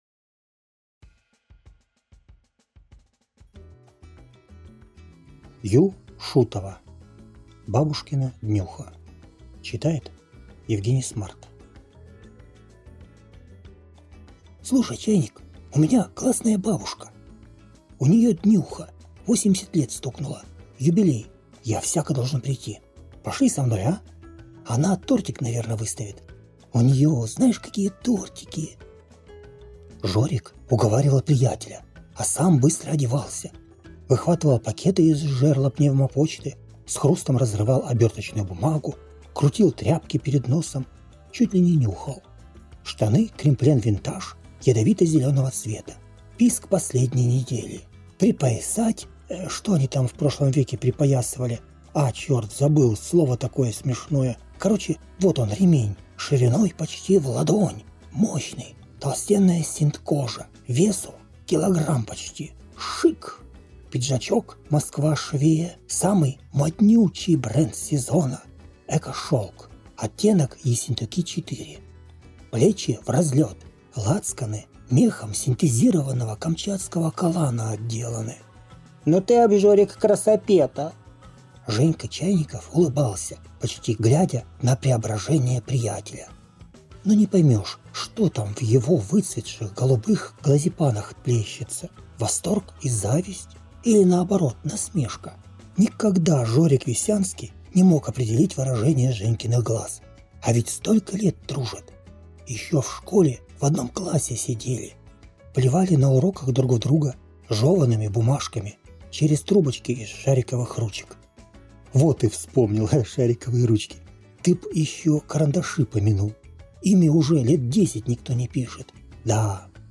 Аудиокнига Бабушкина днюха | Библиотека аудиокниг